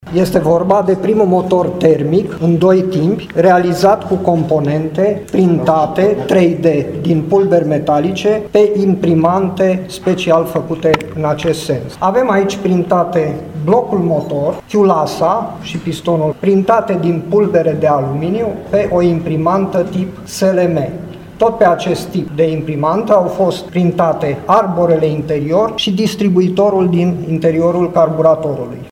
În cadrul unei conferințe de presă a fost prezentat prototipul, care este primul de acest gen din lume, realizat prin printare 3D.